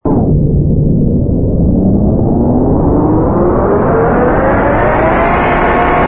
turbine_launch.wav